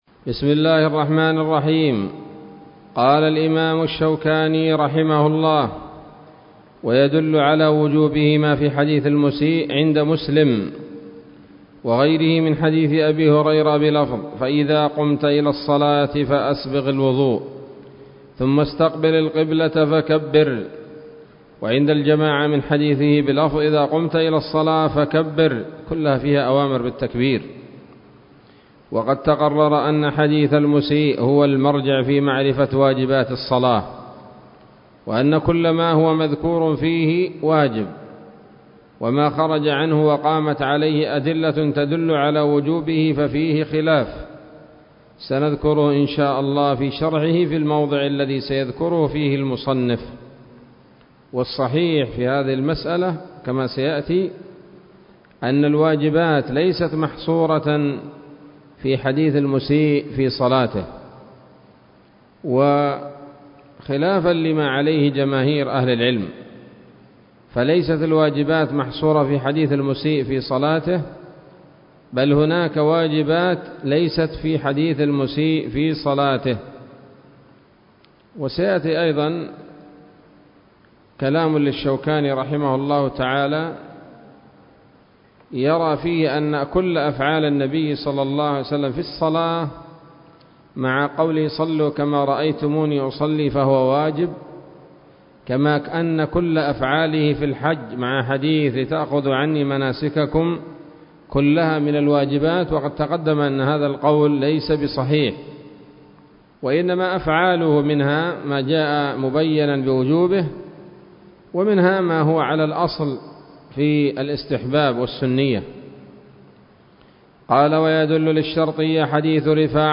الدرس الثاني من أبواب صفة الصلاة من نيل الأوطار